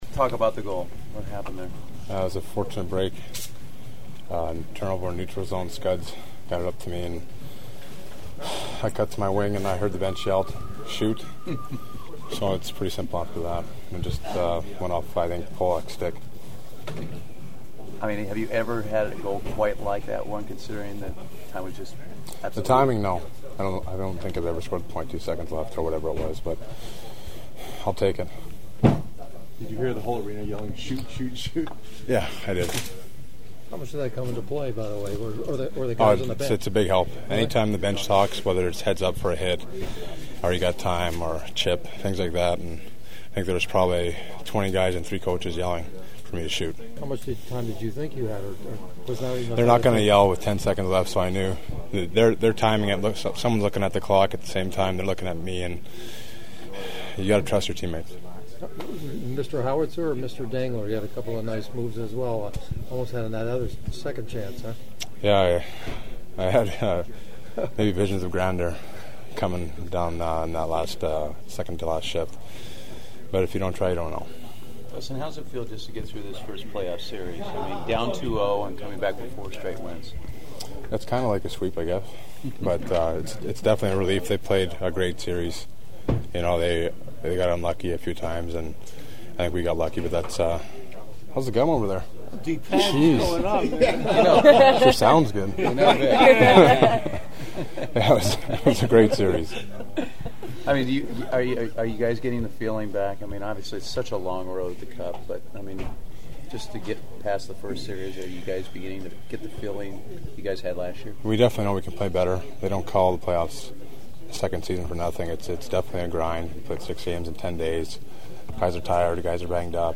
As you can imagine it was a happy locker room after the game and the following will give you a taste of it…
Kings RW Dustin Penner after scoring another playoff game winner and I suggested to him to make some 0.2 T-shirts reminiscent of the Lakers Derek Fisher’s 0.4:
Kings goalie Jonathan Quick who’s ready for an all-California series: